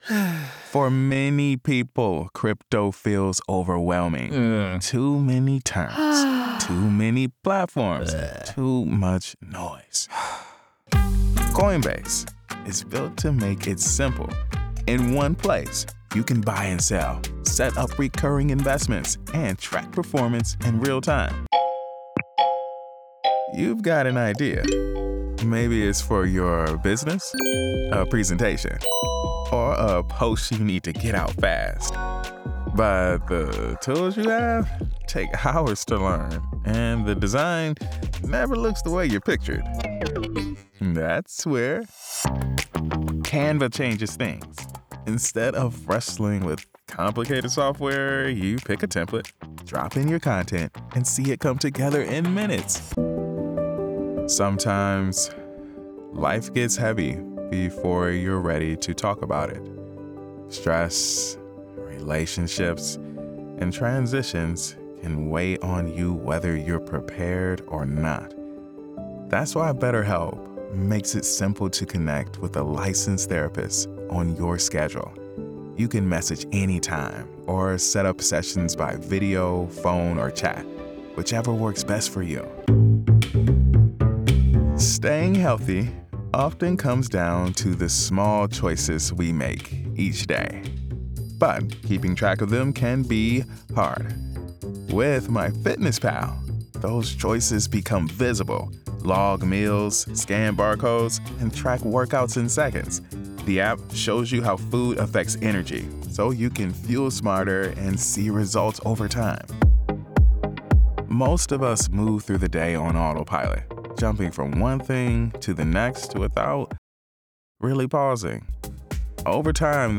Explainer - Upbeat, Engaging, Sincere
From his home studio he narrates voiceovers with an articulate, genuine, conversational, and authentic feel.